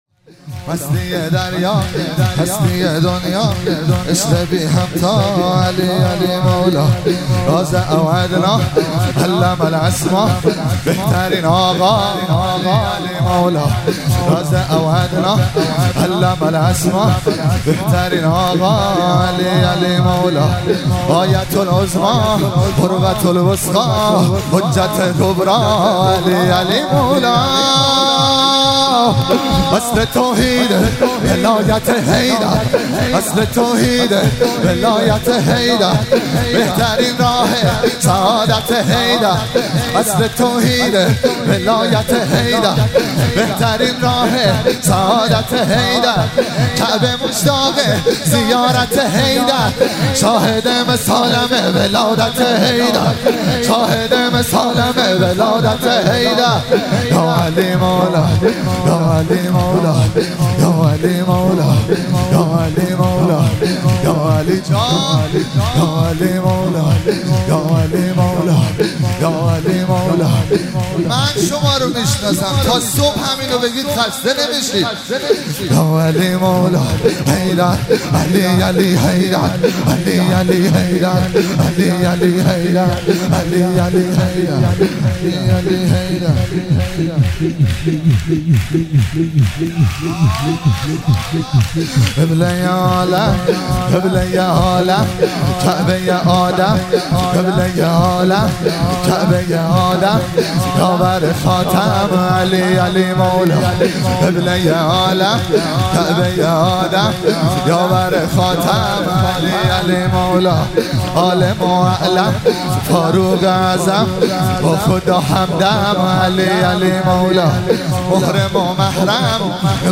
مراسم جشن شب اول ویژه برنامه عید سعید غدیر خم 1444